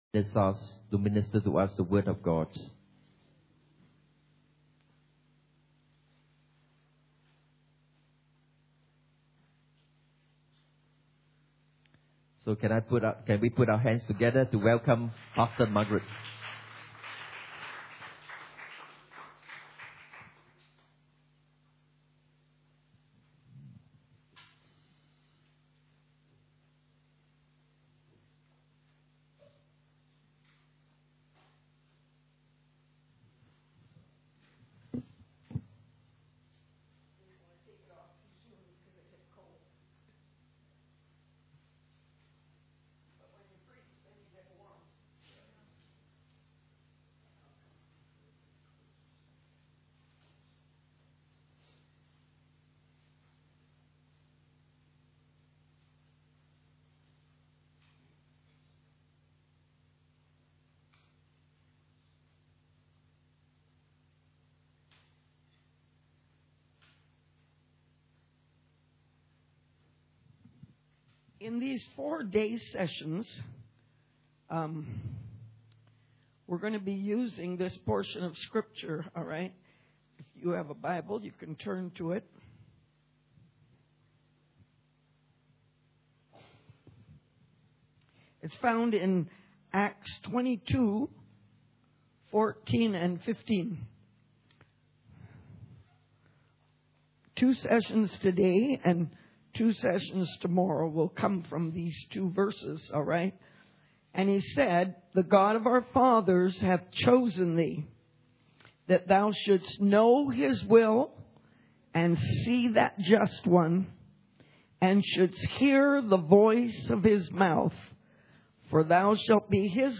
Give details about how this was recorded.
Church Camp 2012 Session 2 – The Privilege of Being Chosen